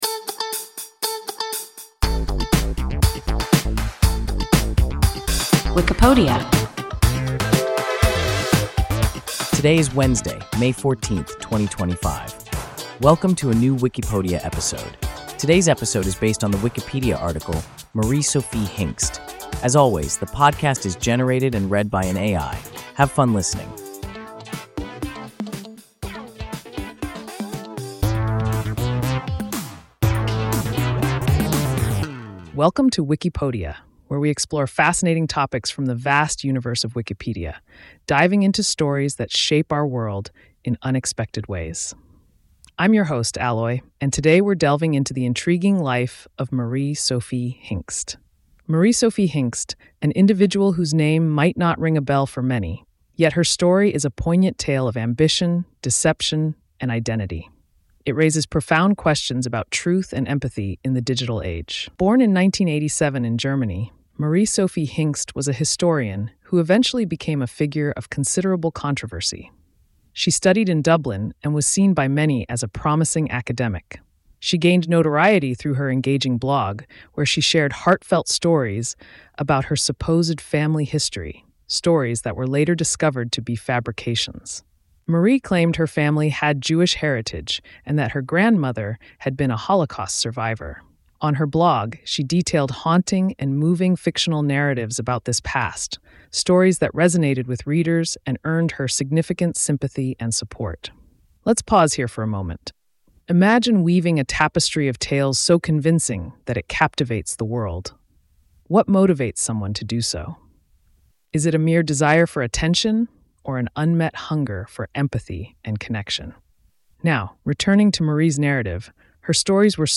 Marie Sophie Hingst – WIKIPODIA – ein KI Podcast